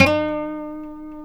E 4 HAMRNYL.wav